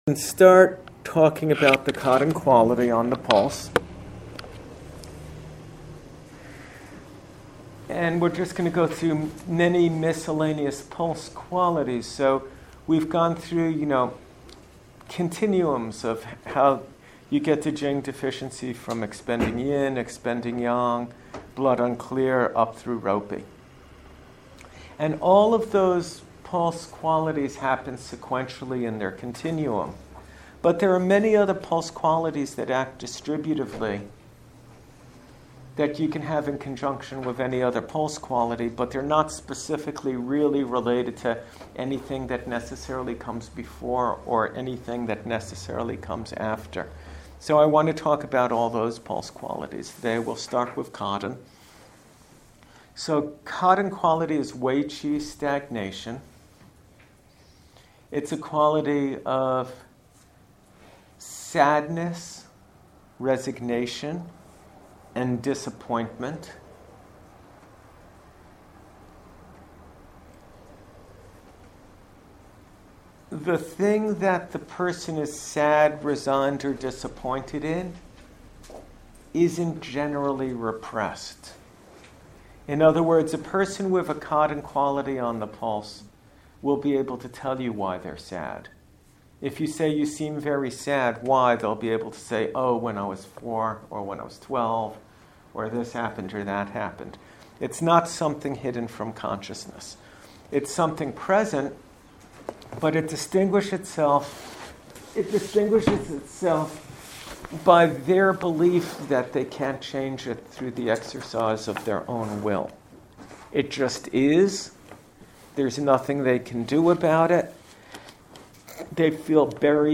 This 40 min lecture is on the Cotton pulse quality. Cotton is felt as a spongy sensation obscuring the pulse.